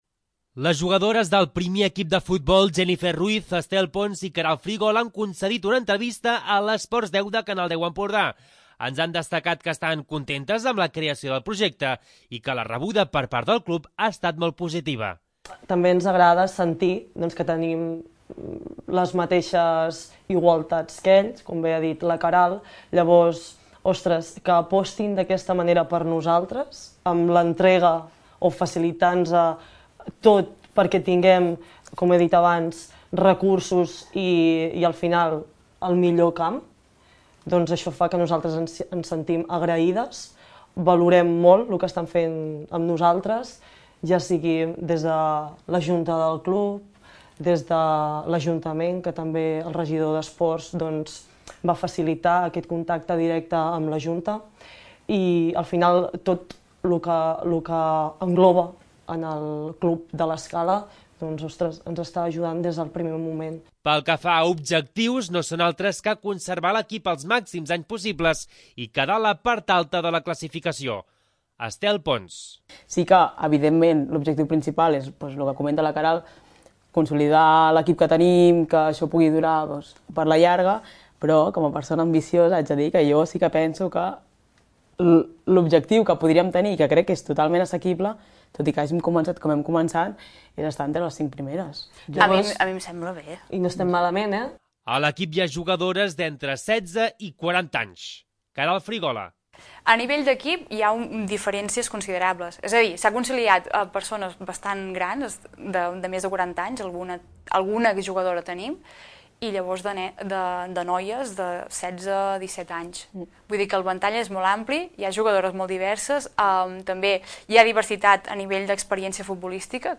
L'Informatiu